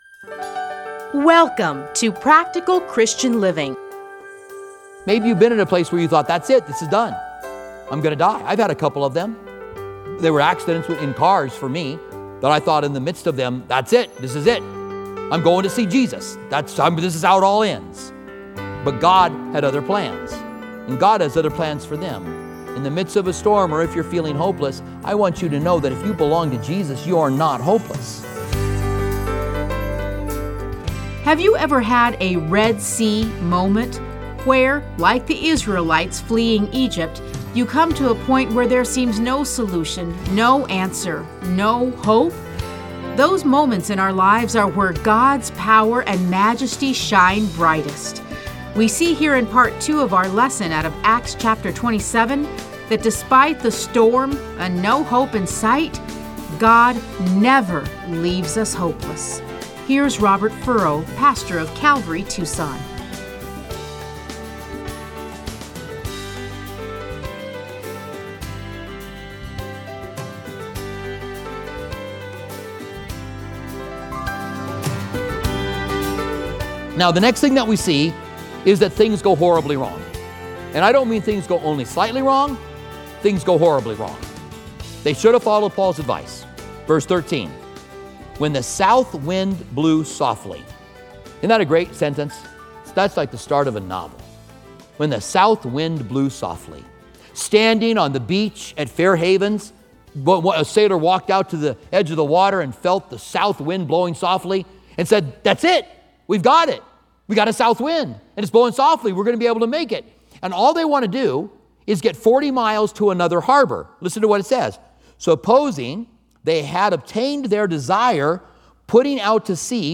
Listen to a teaching from Acts 27:1-26.